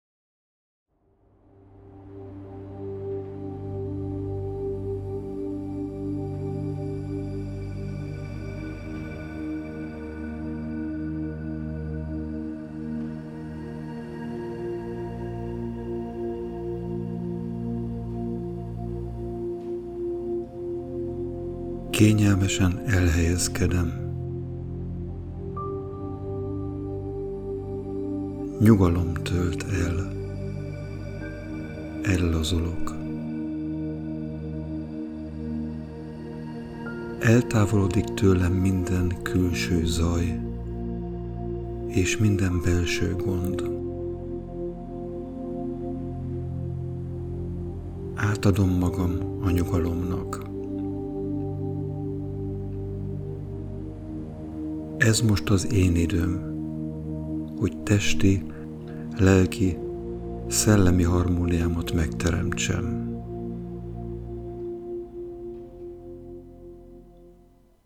Hangosabb zenével
Autogen_trening_minta_zenevel.mp3